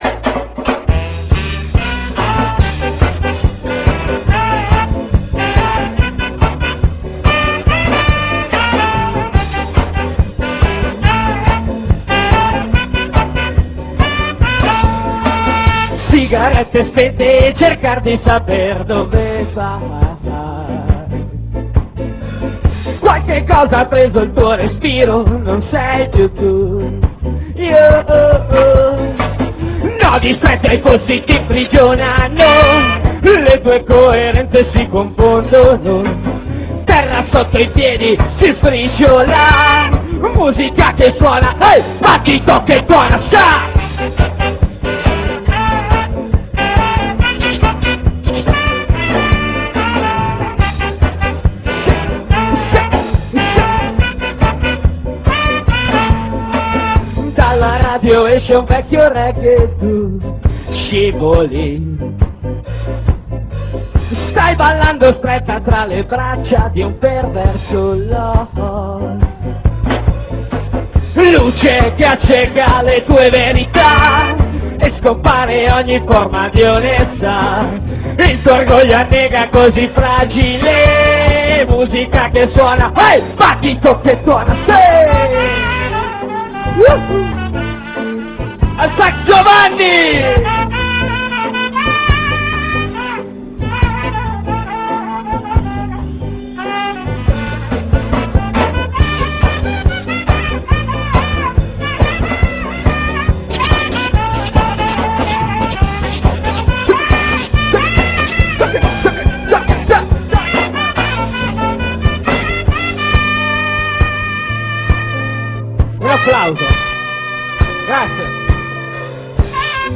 Nel pomeriggio si sono pure dovuti improvvisare fonici...